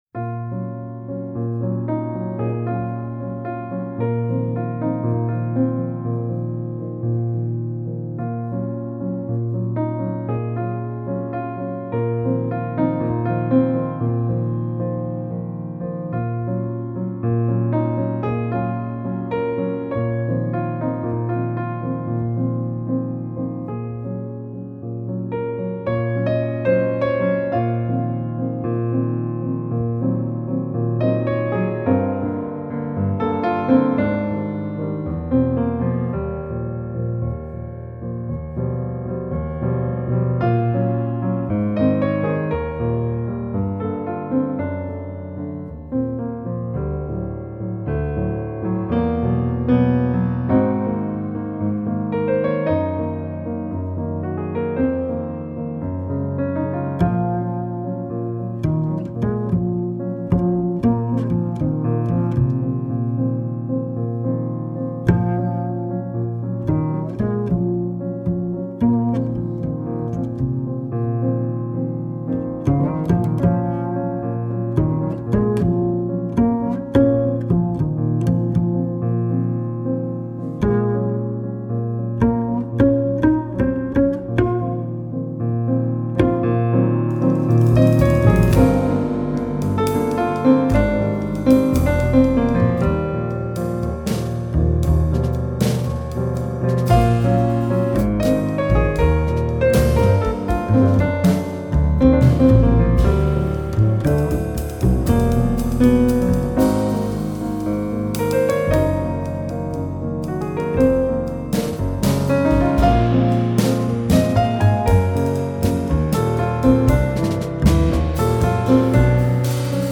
Geräuschhaft, traumverloren oder ekstatisch.
Piano, Vocals
Bass
Schlagzeug